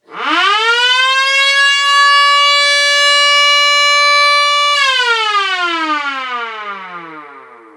Sirena elettromeccanica medio grande in alluminio.
Prestazioni sonore maggiori rispetto al modello MS.1.